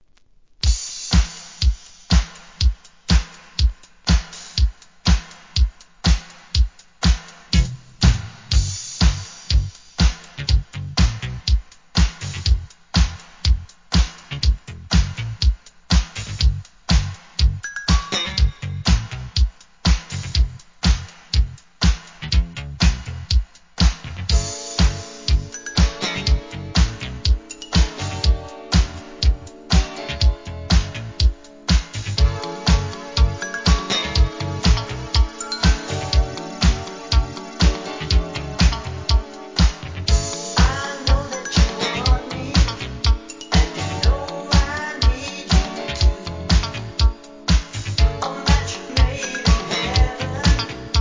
SOUL/FUNK/etc...
都会的なサウンドでCOOLです!